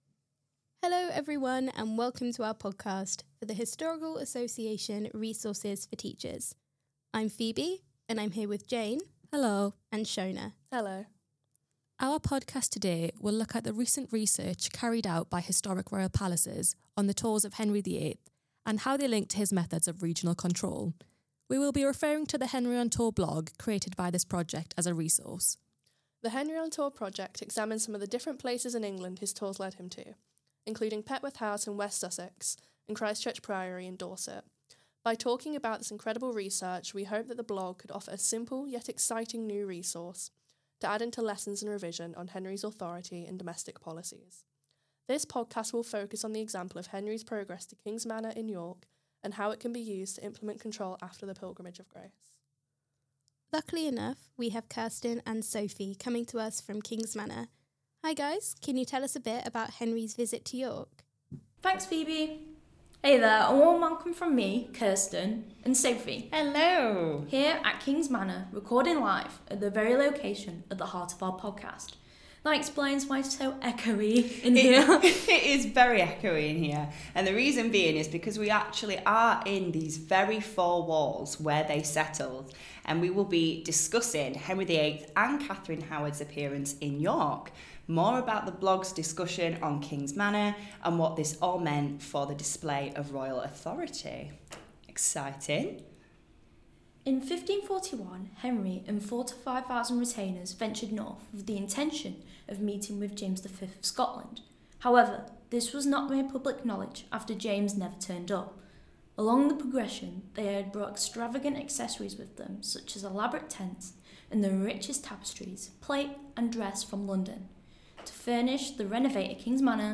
Henry VIII on Tour This podcast explores the impact of Henry VIII’s royal progresses on his authority, referring to the recent research conducted by the Henry on Tour blog with Historic Royal Palaces. Recording from King’s Manor in York the podcast especially looks at Henry VIII’s progress to York in 1541 as a response to the Pilgrimage of Grace.